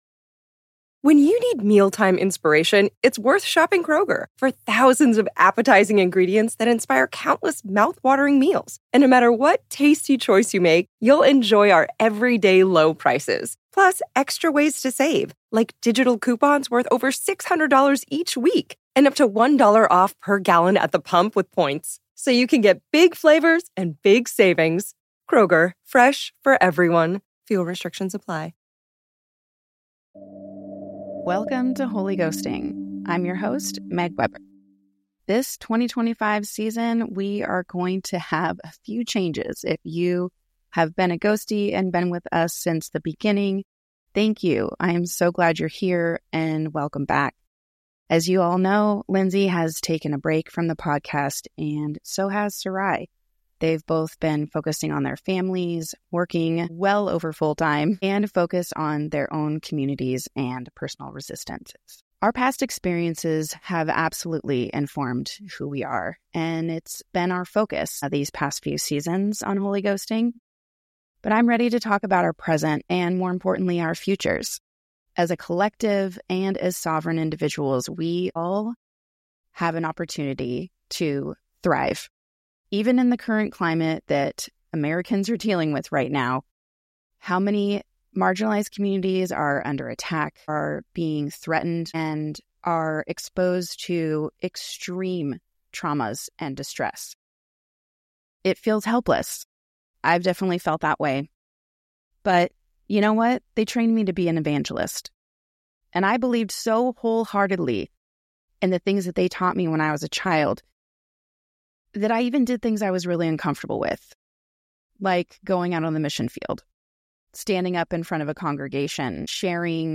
In each episode, we tackle healing from our youthful experiences and the harm we endured and perpetrated as American Evangelicals, and we laugh, we're sarcastic ladies.